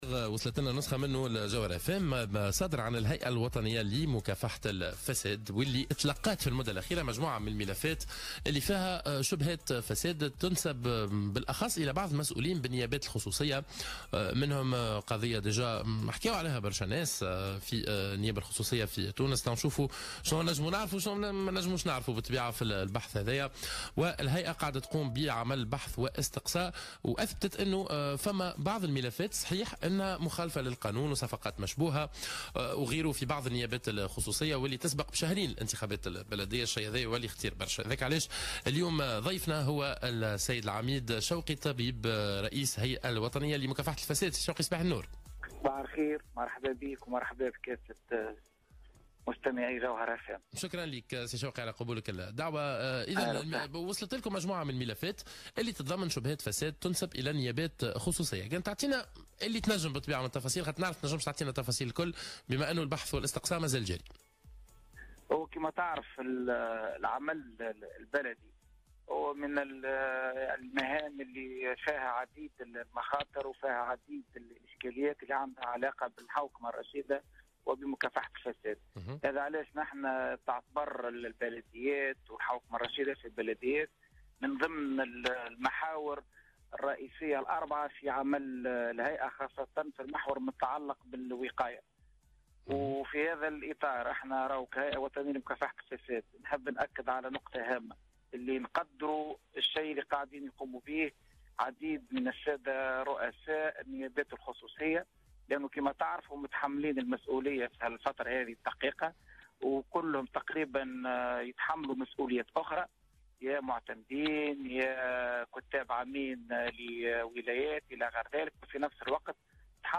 أكد رئيس الهيئة الوطنية لمكافحة الفساد شوقي الطبيب في مداخلة له في صباح الورد اليوم الخميس 1 مارس 2018 أنه تلقى مؤخرا مجموعة من الملفات المتضمنة لشبهات فساد تنسب إلى بعض المسؤولين في النيابات الخصوصية.